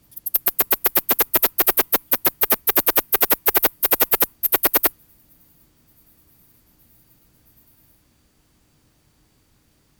peninsular shieldback
10 s of calling song and waveform. San Diego County, California; 21.0°C. R22-10.